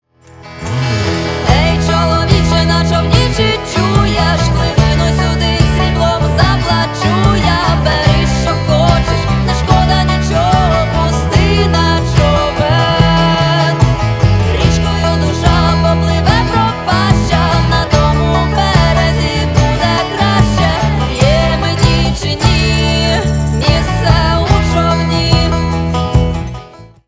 • Качество: 320, Stereo
фолк
украинский рок
фолк-рок
украинский женский голос